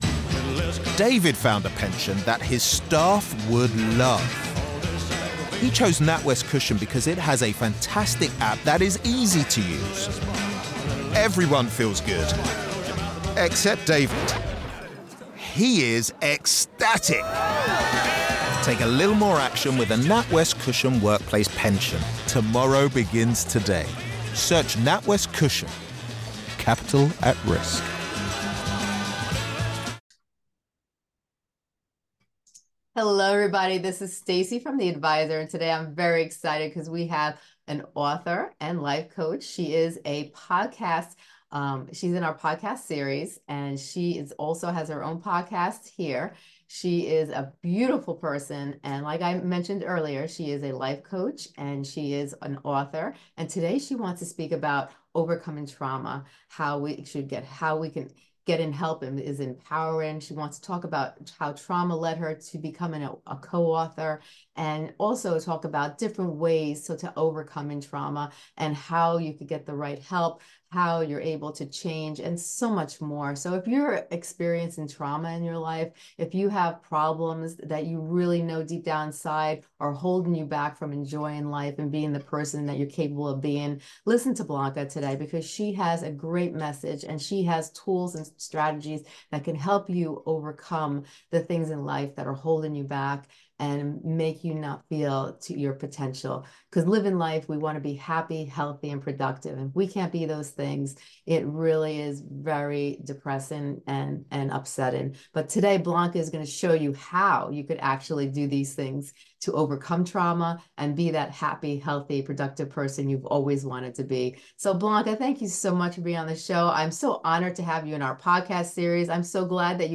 In this thought-provoking conversation